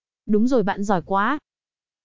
Âm thanh Đúng Rồi Bạn Giỏi Quá
Bên trên là hiệu ứng âm thanh đúng rồi bạn giỏi quá. Mẫu âm thanh này ghi âm tiếng nói đúng rồi bạn giỏi quá.